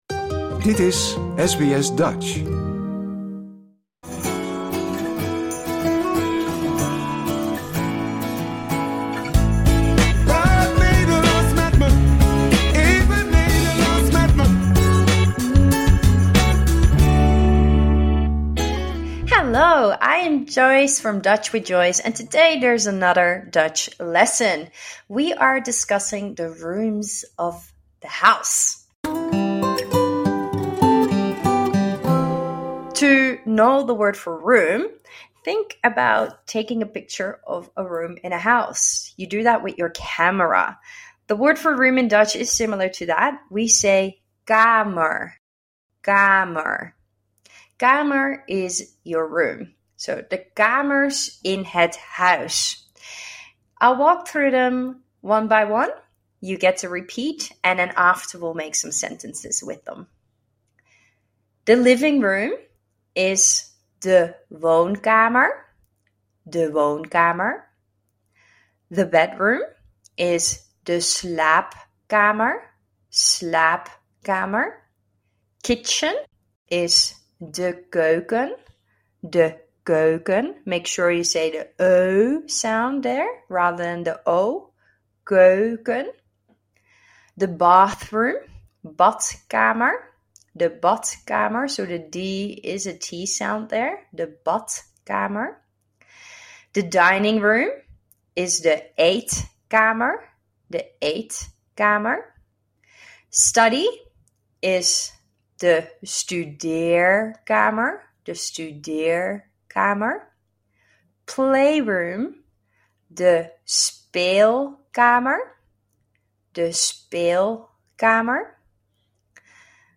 In this podcast series you will learn a few Dutch words and pronunciations every week in a few minutes.